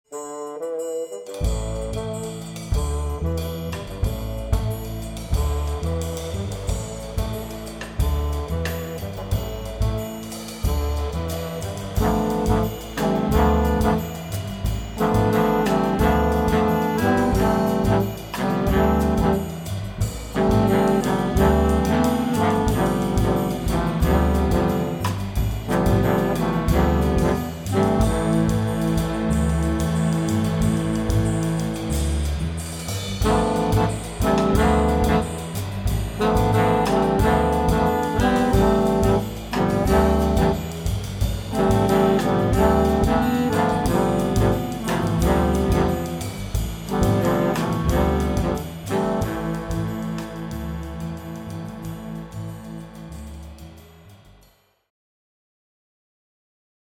ben sostenuti dal sax tenore
tromba e flicorno
contrabbasso
batteria